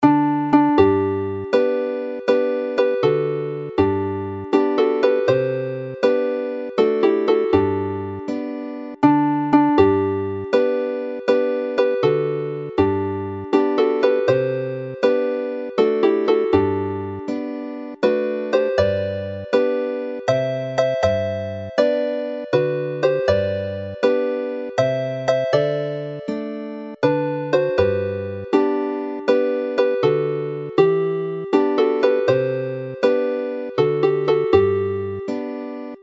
Play the melody with harmony